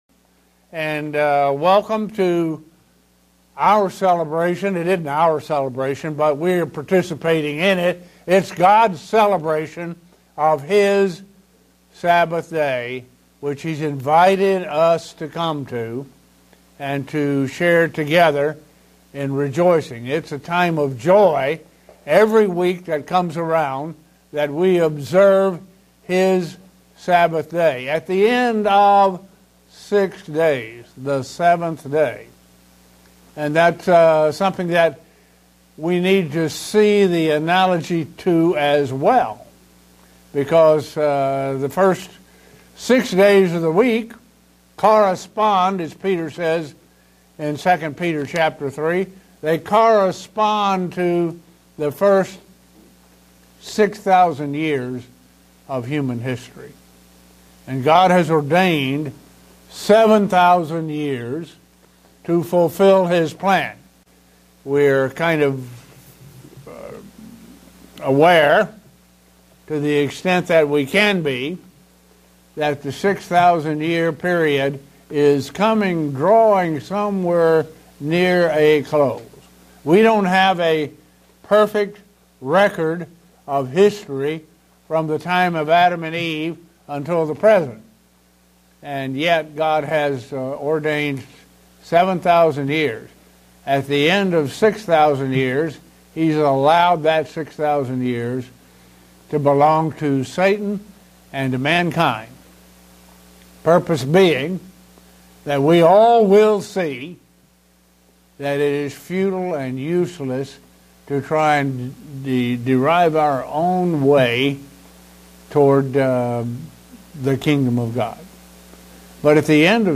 Given in Buffalo, NY
Print Primitive Christianity its origin and where it can be found today. sermon Studying the bible?